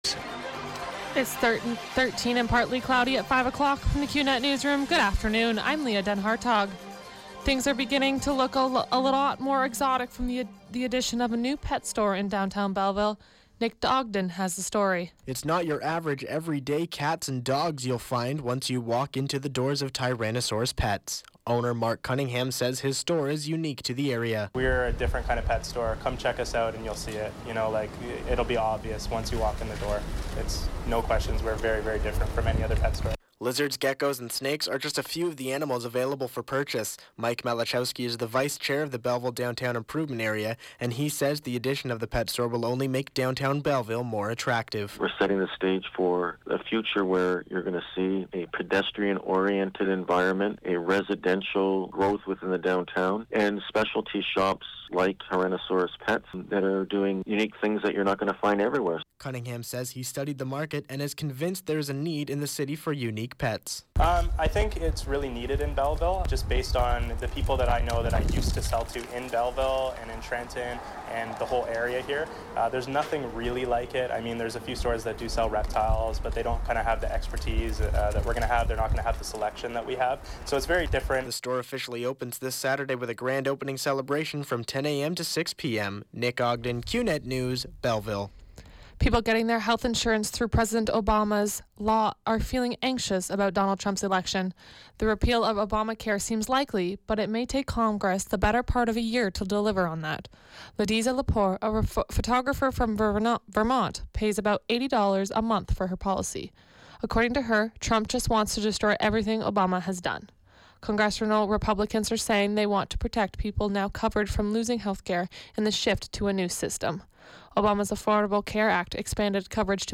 91X FM Newscast – Thursday, Nov. 10, 2016, 5 p.m.